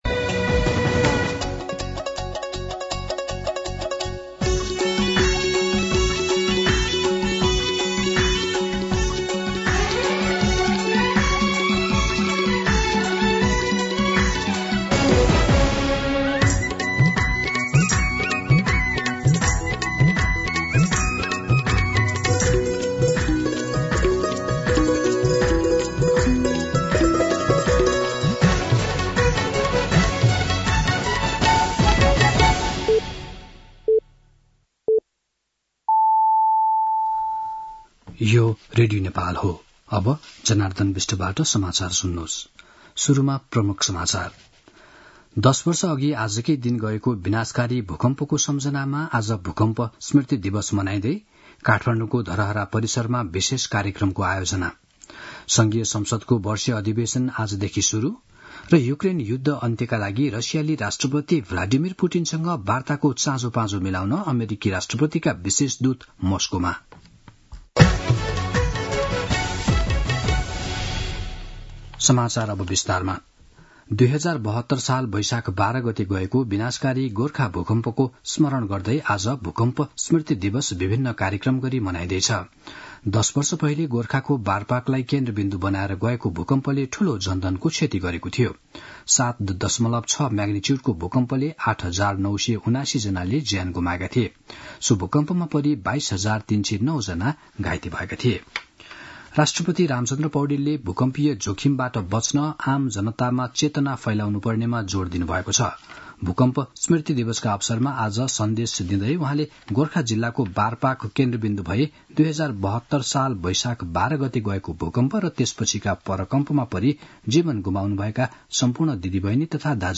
दिउँसो ३ बजेको नेपाली समाचार : १२ वैशाख , २०८२
3-pm-Nepali-News-5.mp3